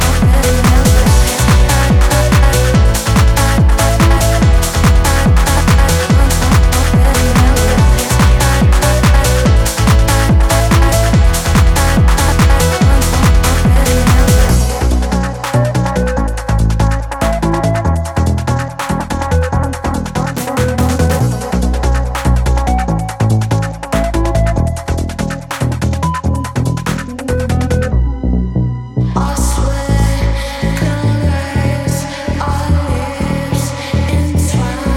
Techno Dance
Жанр: Танцевальные / Техно